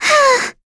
Lavril-Vox_Attack1.wav